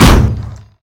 platepop.ogg